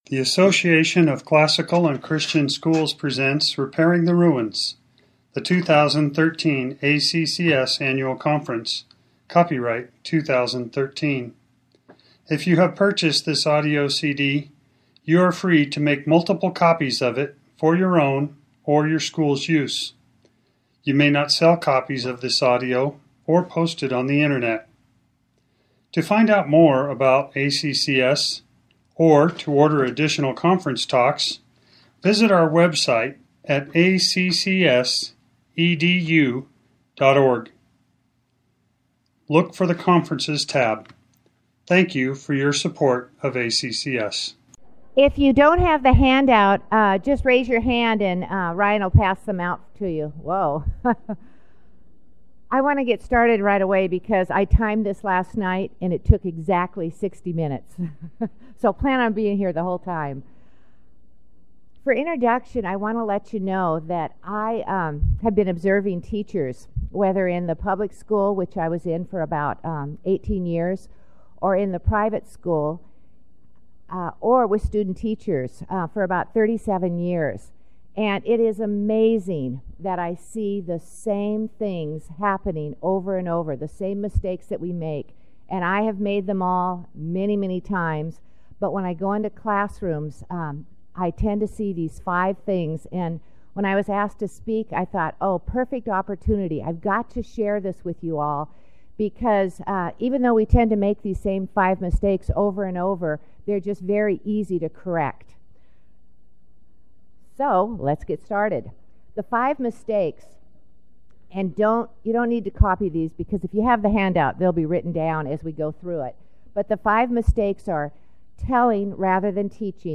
2013 Workshop Talk | 0:51:13 | All Grade Levels, General Classroom
The Association of Classical & Christian Schools presents Repairing the Ruins, the ACCS annual conference, copyright ACCS.